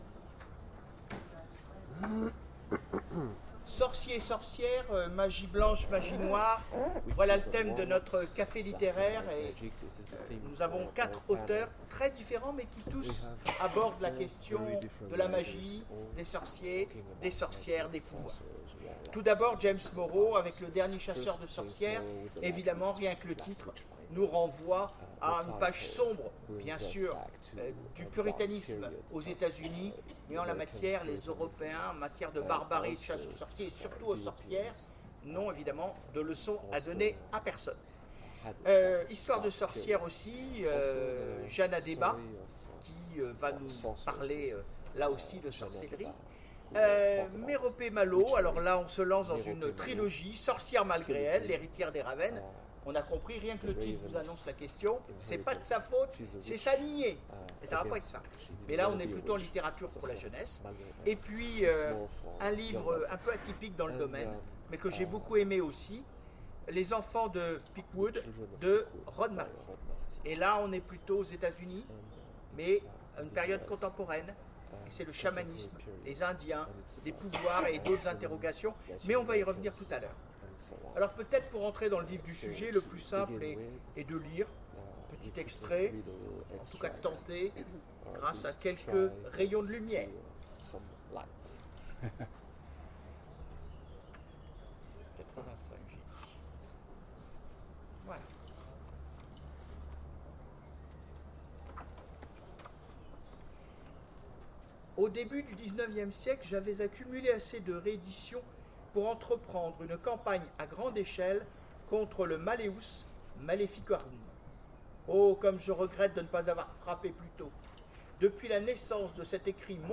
Imaginales 2016 : Conférence Sorciers et sorcières…